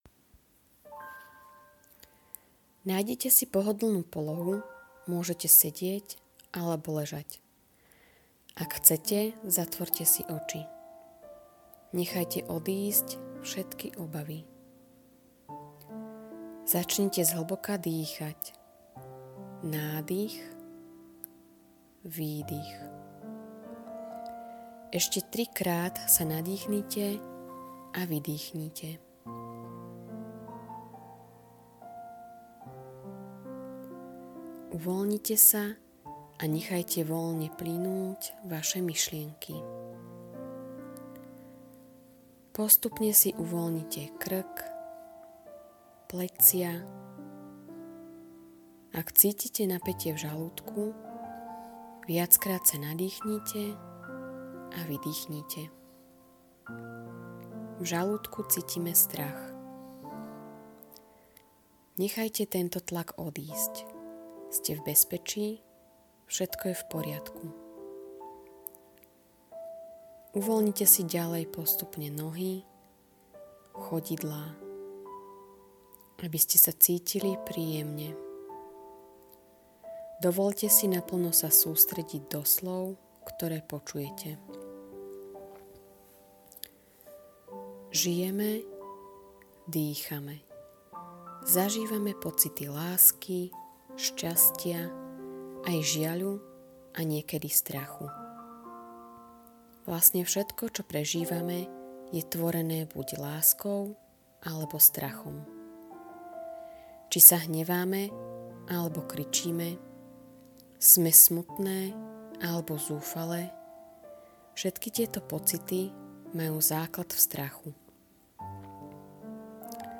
Meditácia ochrana pred strachom © Chránené autorským právom Krátky popis: Všetko čo cítime je tvorené buď láskou alebo strachom.
meditacia-ochrana-pred-strachom.mp3